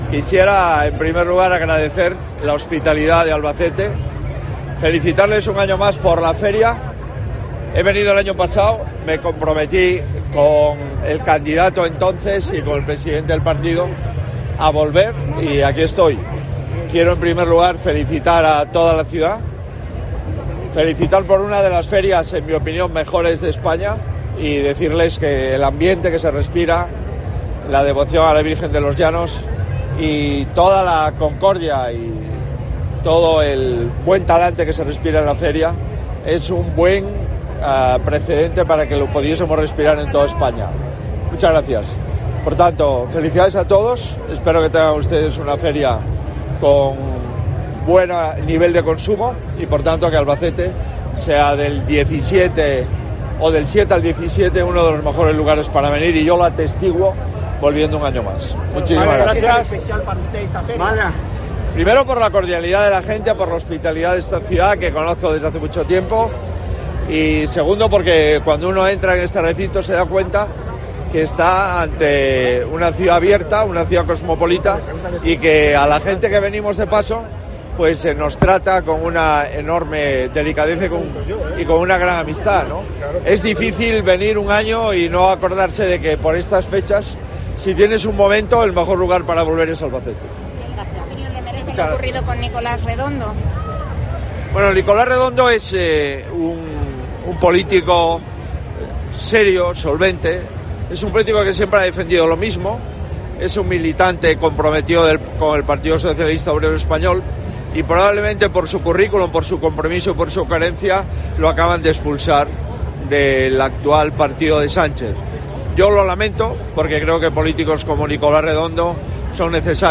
Tras las fotos y vídeos de rigor, Núñez Feijóo ha atravesado la puerta central del Recinto Ferial para atender durante tres minutos a la nube de medios locales, regionales y nacionales que esperaban una declaración suya, en principio no anunciada.
Alberto Núñez Feijóo: declaraciones en la Feria de Albacete